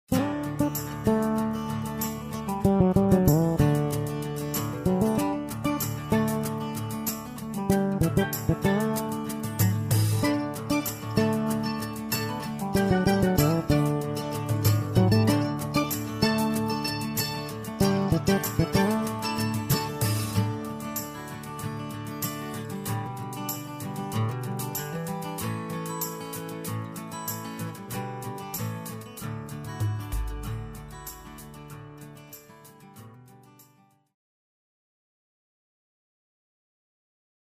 Karaoke Soundtrack
Backing Track without Vocals for your optimal performance.